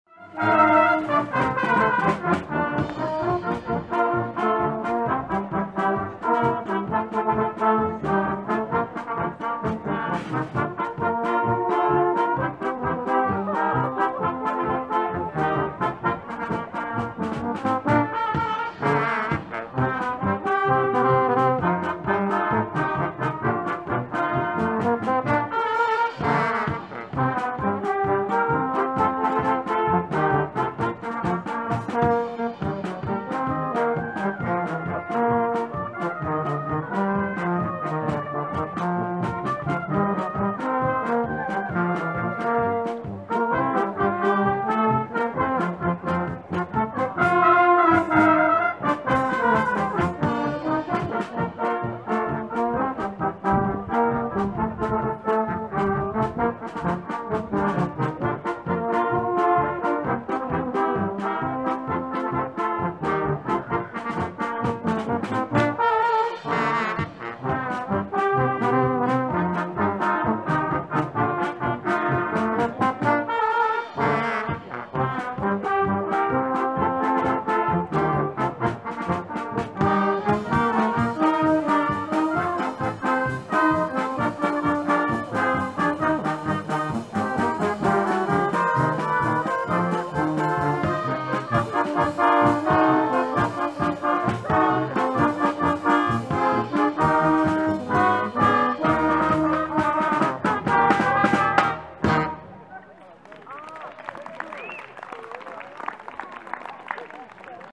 Academy Band
It has a growing membership of players of mixed abilities, aged from 8 years to older than we are prepared to say…!